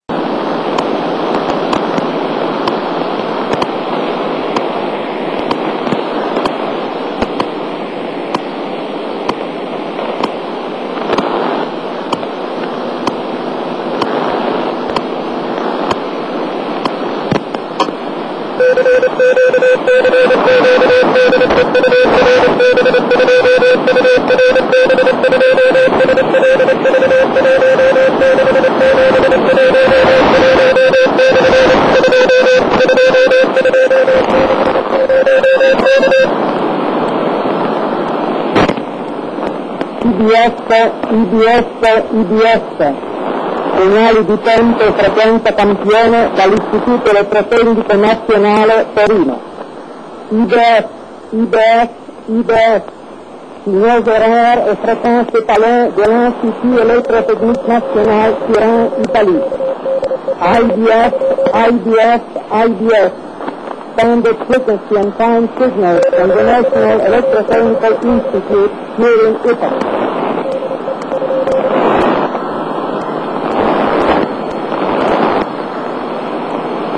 La stazione di tempo e frequenza IBF trasmetteva da Torino in onde corte, sulla frequenza di 5 Mhz, il segnale generato dall'Istituto Elettrotecnico Nazionale (IEN).
segnale di identificazione di IBF